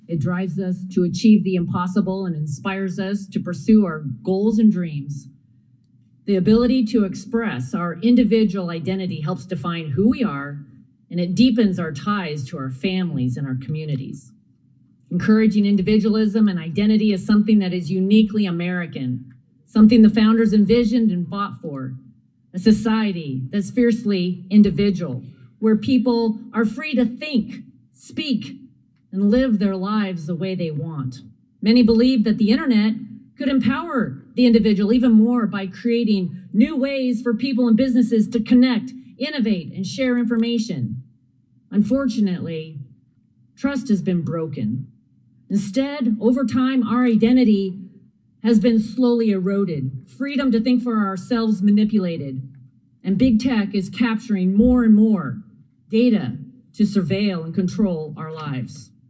The Spokane Republican told the committee that our identity is at the core of what makes us human.